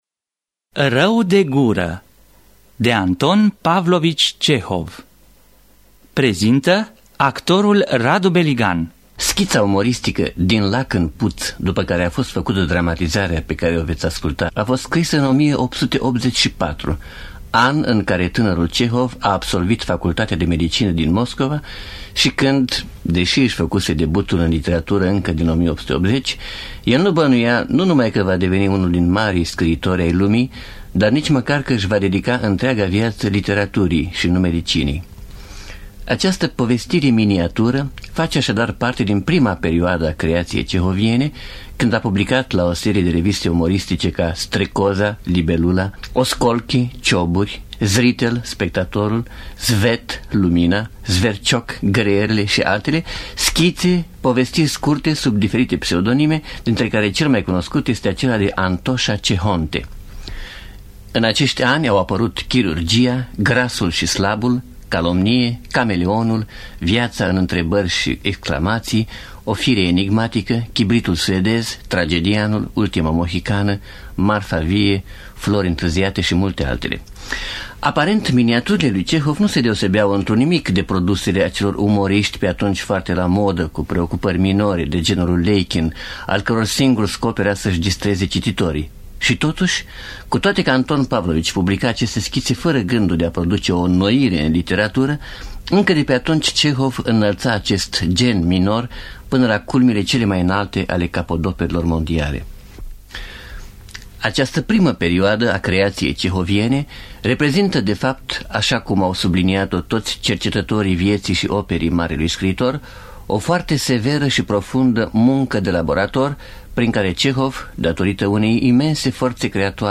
Dramatizarea radiofonică: Mihail Drumeş.